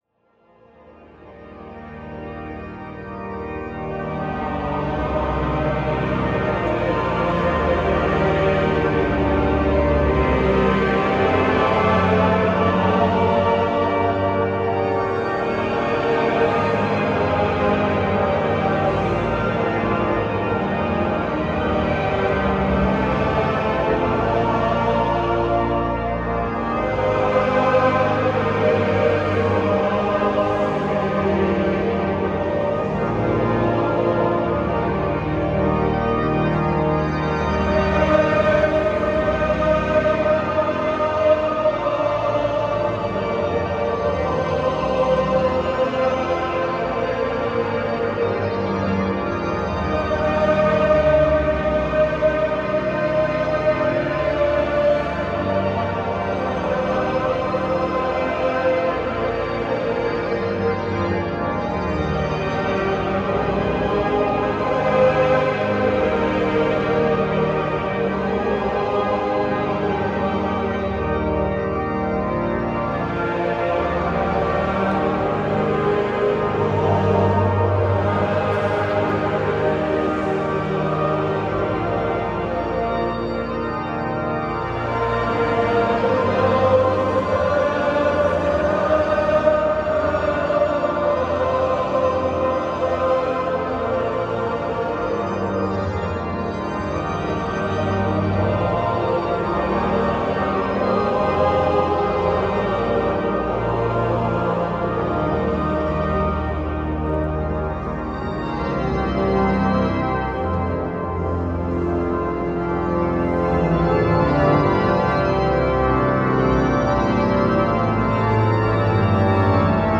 Samenzang Grote Kerk Dordrecht
Beluister hier een gedeelte van een live opname vanuit de Dordtse Domkerk. U hoort hier een door Stolk Orgels geplaatste Johannus Ecclesia T-355 tijdens de begeleiding van samenzang